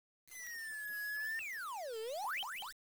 yawn.wav